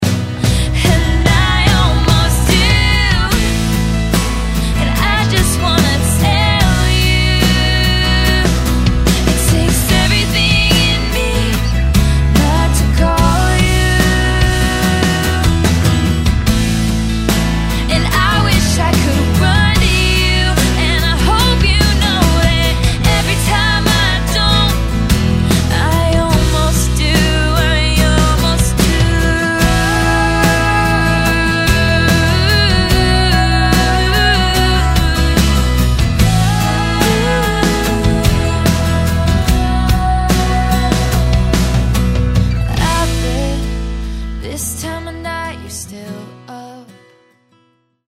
романтические рингтоны